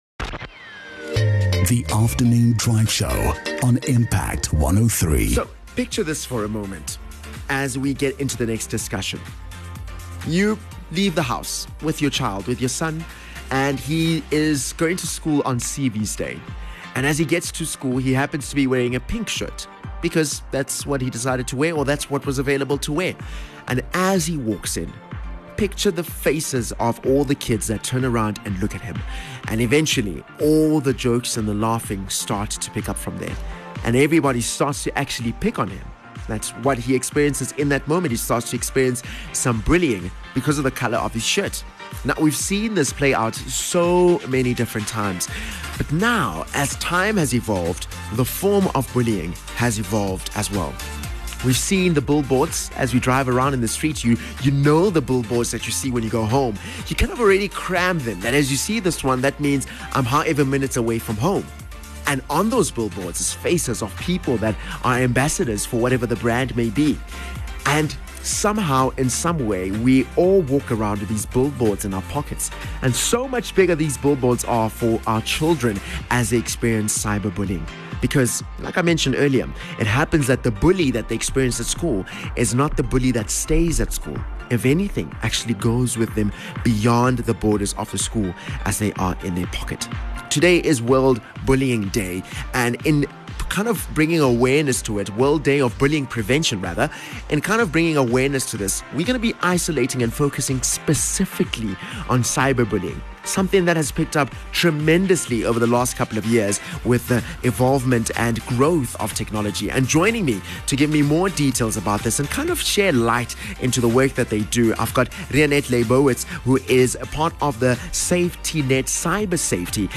ENGLISH SOUTH AFRICA